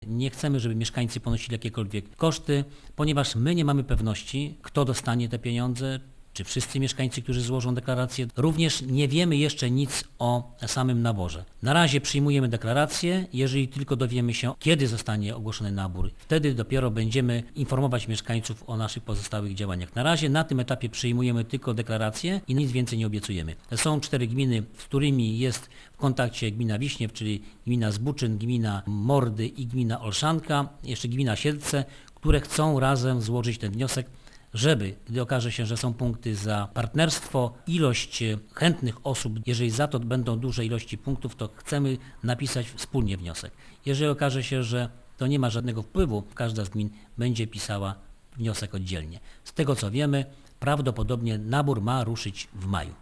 Wójt Krzysztof Kryszczuk podkreśla, że na tym etapie starań o unijne wsparcie, mieszkańcy nie ponoszą żadnych opłat: